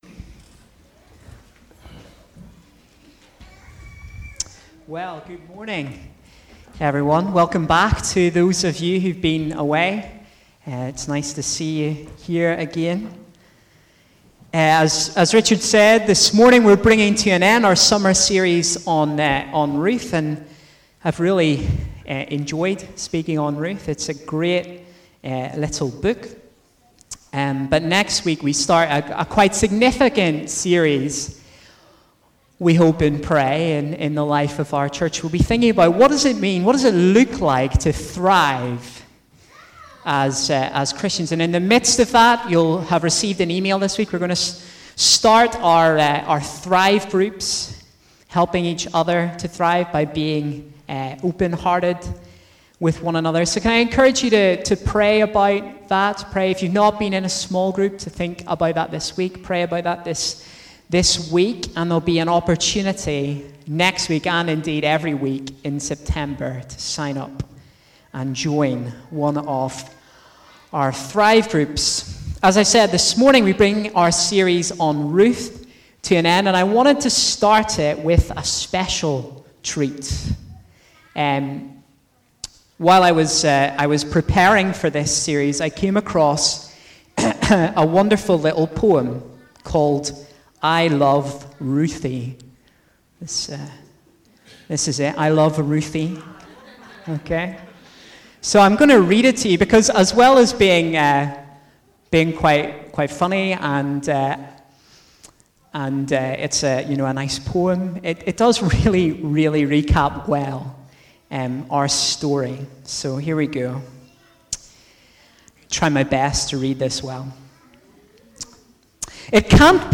A message from the series "Ruth."